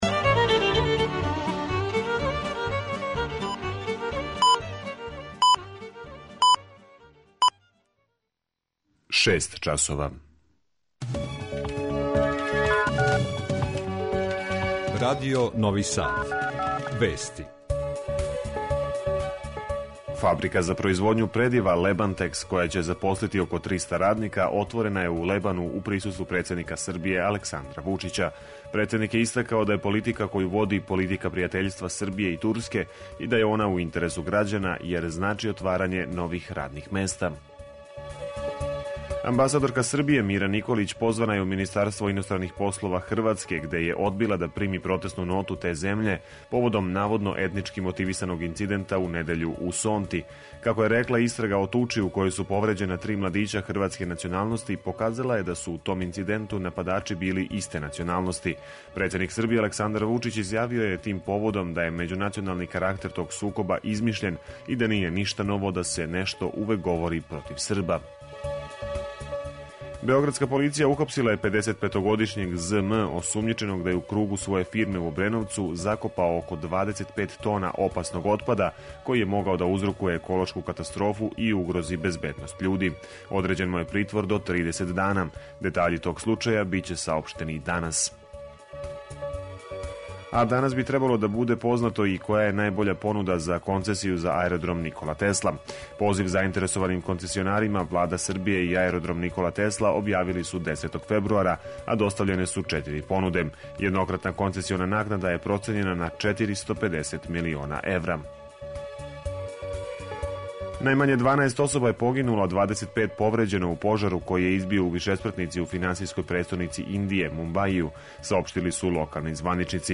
Три градоначелника у три студија